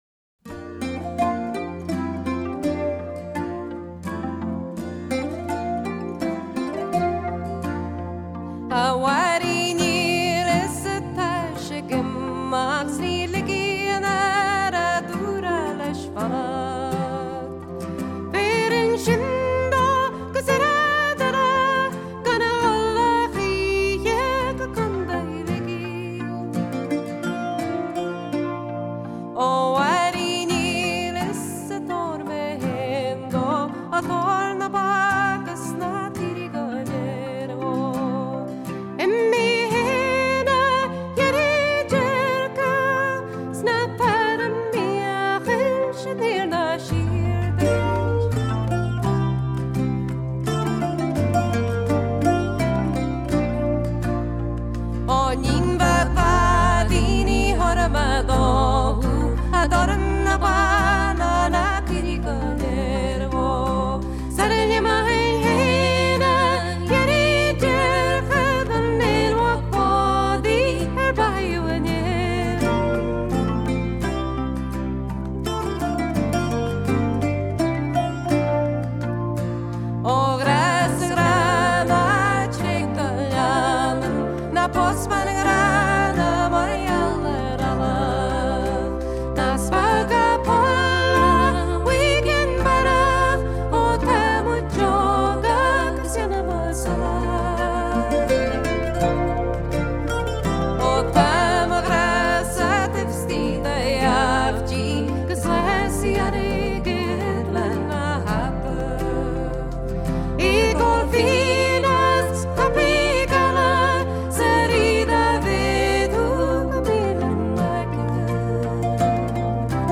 Vocals
Bouzouki, Mandolin, Bodhran, Keyboards
Backing Vocals, Keyboards
Guitar
Uilleann Pipes, Whistle